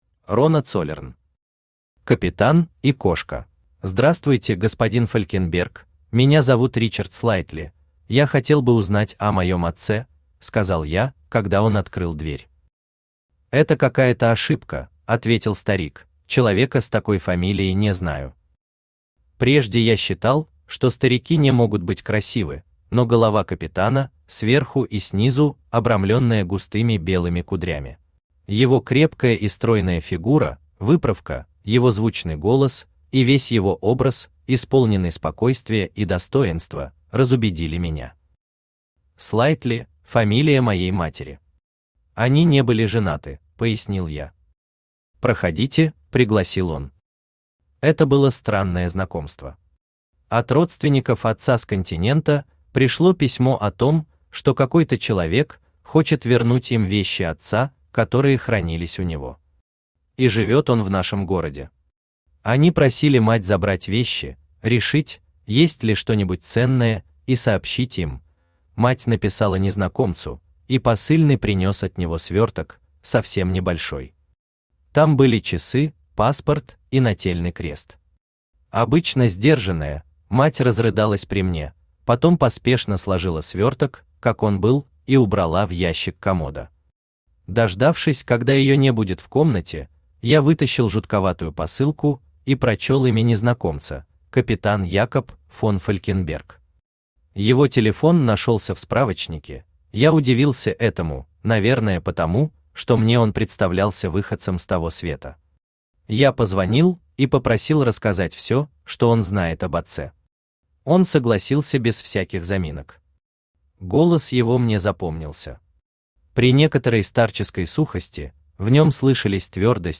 Аудиокнига Капитан и кошка